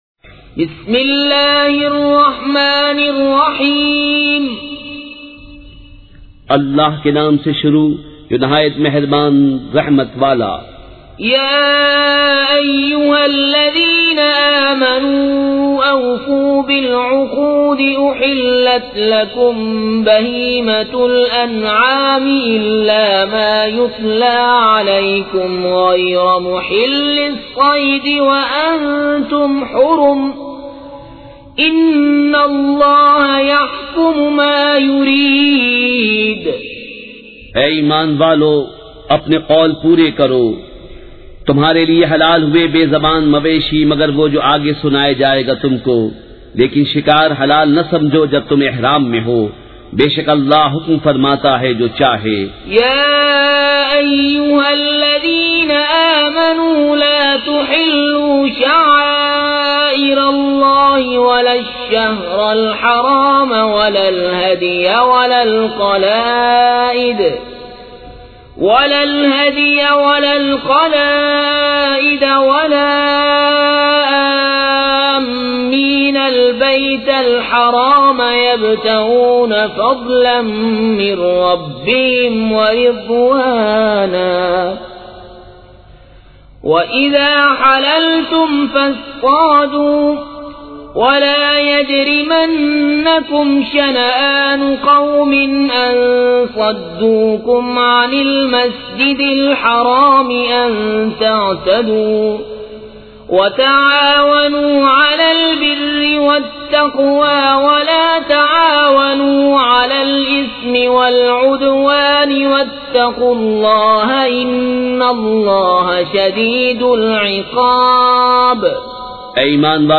سورۃ المائدہ مع ترجمہ کنزالایمان ZiaeTaiba Audio میڈیا کی معلومات نام سورۃ المائدہ مع ترجمہ کنزالایمان موضوع تلاوت آواز دیگر زبان عربی کل نتائج 7172 قسم آڈیو ڈاؤن لوڈ MP 3 ڈاؤن لوڈ MP 4 متعلقہ تجویزوآراء